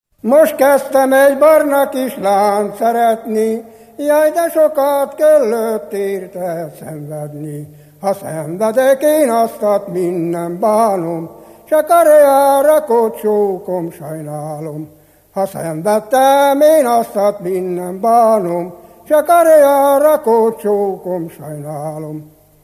Felföld - Hont vm. - Palást
Műfaj: Dudanóta
Stílus: 6. Duda-kanász mulattató stílus